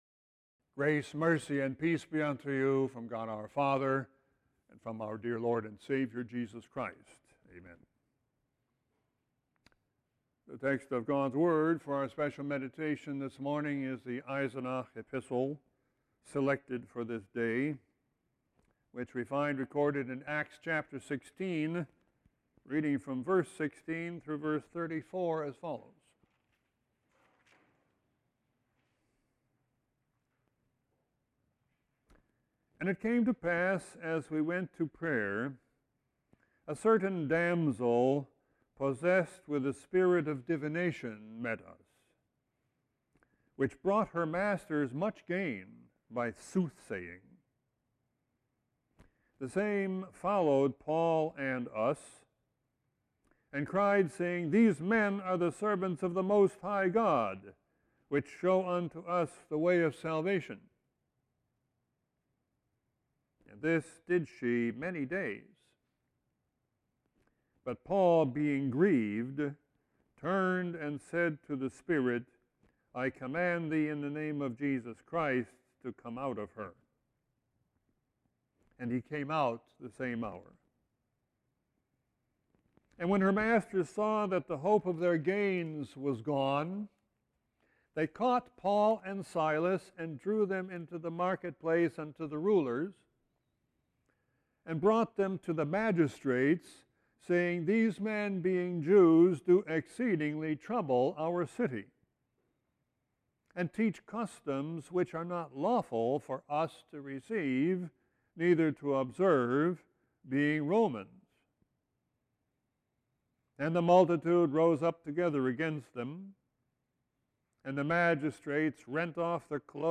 Sermon 7-17-16.mp3